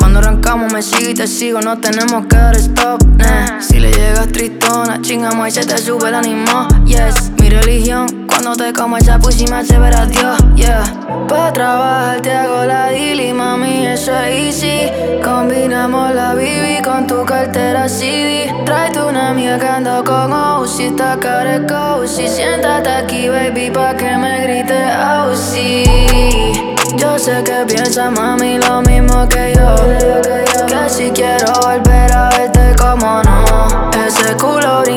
Urbano latino Latin Hip-Hop Rap
Жанр: Хип-Хоп / Рэп / Латино